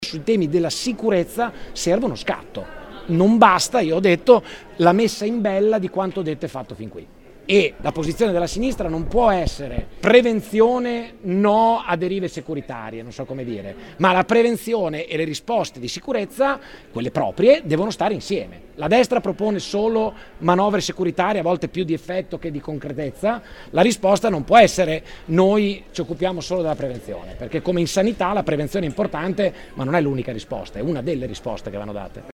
“Sulla sicurezza serve uno scatto” ha detto De Pascale: